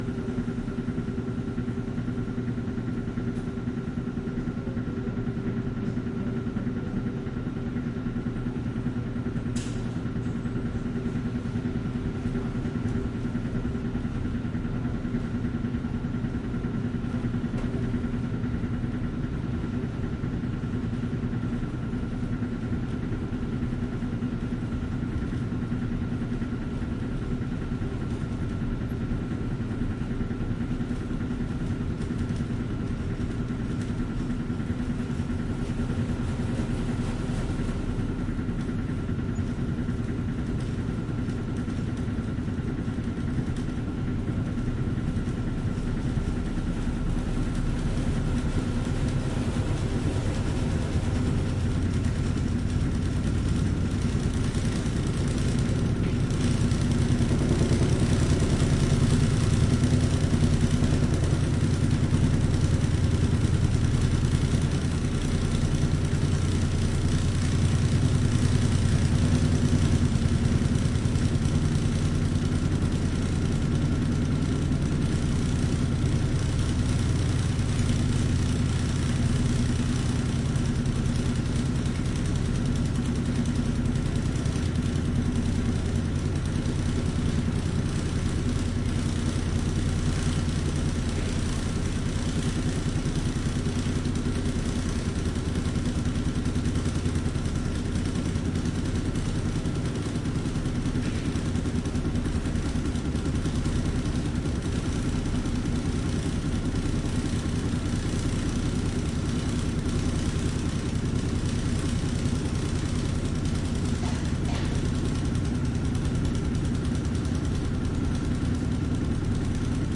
自助洗衣店 " 自助洗衣店的洗衣机发出响声 振动4
描述：洗衣店洗衣机洗衣机拨浪鼓vibrate4.flac
Tag: 马赫INES 洗衣店 震动 撞击 洗衣机 洗衣机